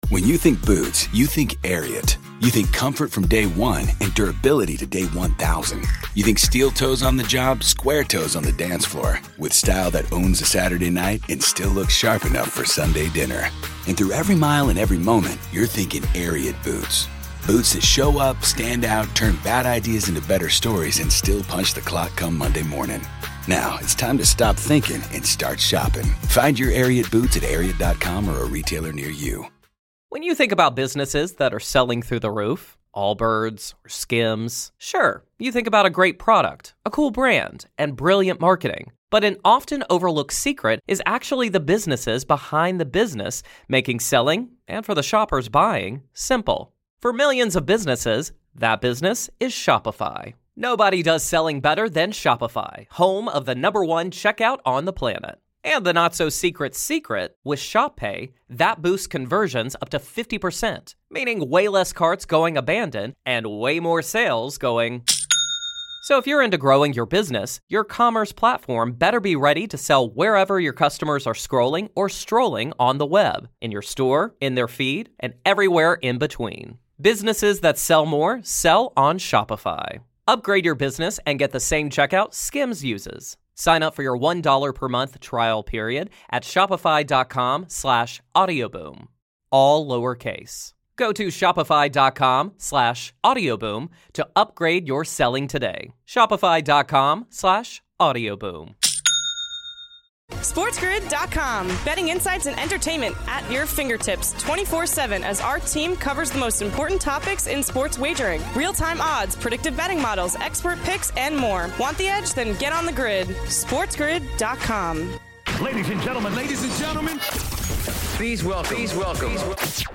flying solo for the show